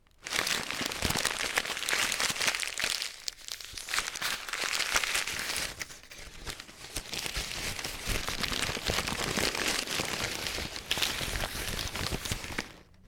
rustle.paper_3
cruble noise paper rip rustle scratch tear sound effect free sound royalty free Nature